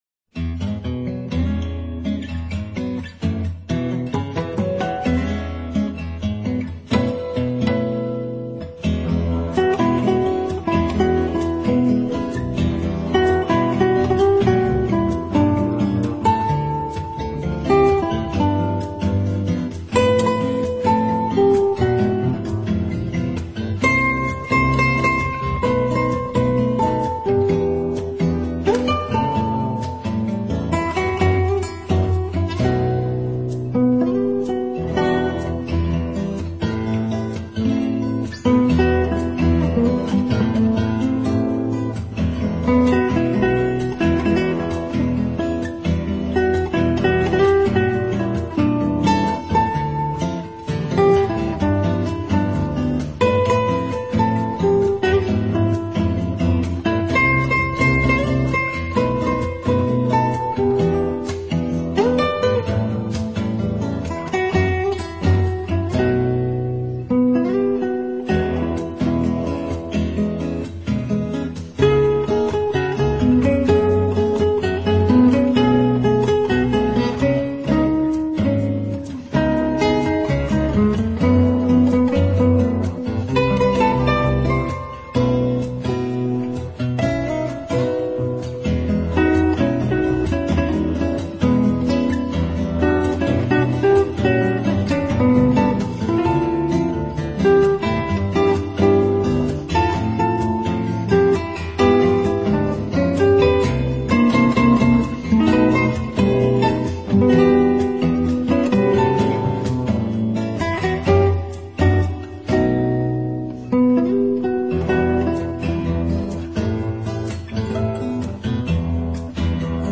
音乐类型：Jazz 爵士
音乐风格：New Age, Smooth Jazz,Contemporary,Instrumental
轻盈，音乐的轻松关键在于放飞了我们的灵幻，无拘无束的，没有困扰的。
英国双人吉他组合